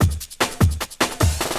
50 LOOP03 -R.wav